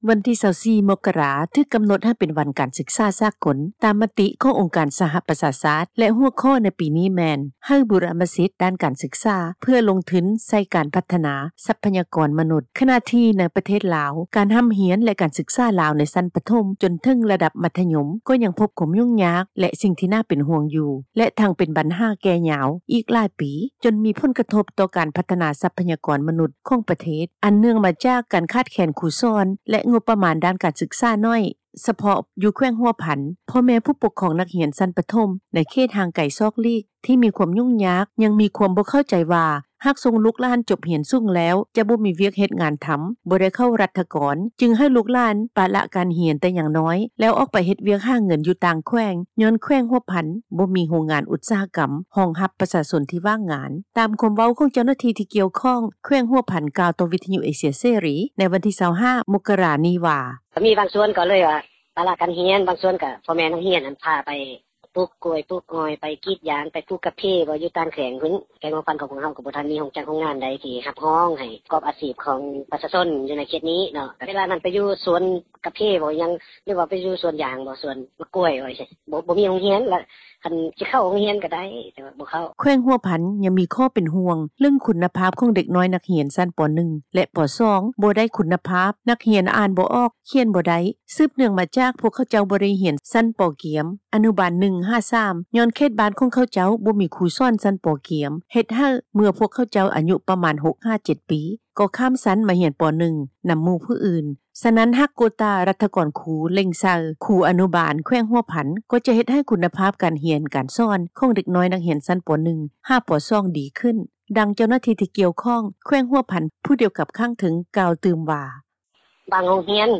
ຕາມຄວາມເວົ້າ ຂອງເຈົ້າໜ້າທີ່ ທີ່ກ່ຽວຂ້ອງ ແຂວງຫົວພັນ ກ່າວຕໍ່ວິທຍຸ ເອເຊັຽ ເສຣີ ໃນ ວັນທີ 25 ມົກຣາ ນີ້ວ່າ:
ດັ່ງ ເຈົ້າໜ້າທີ່ ທີ່ກ່ຽວຂ້ອງ ແຂວງສວັນນະເຂດ ກ່າວໃນມື້ດຽວກັນນີ້ວ່າ:
ດັ່ງ ປະຊາຊົນ ແຂວງຫົວພັນ ກ່າວໃນມື້ດຽວກັນນີ້ວ່າ: